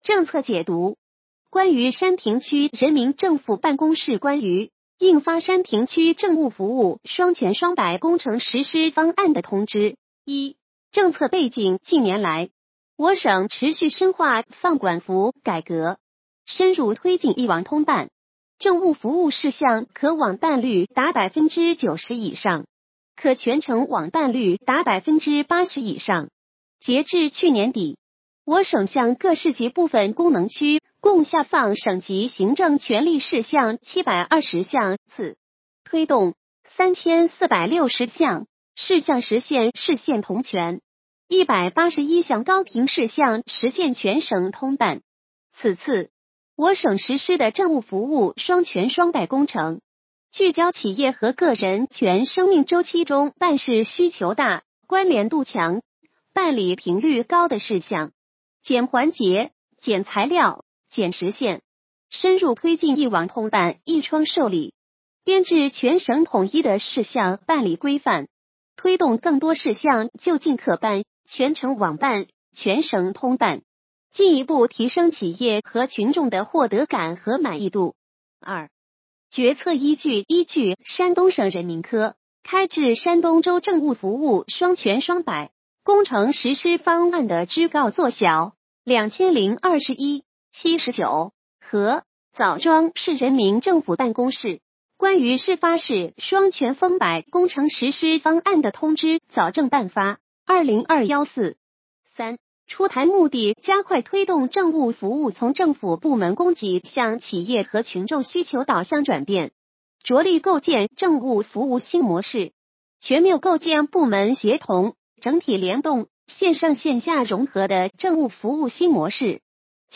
语音解读：山亭区人民政府办公室关于印发山亭区政务服务“双全双百”工程实施方案的通知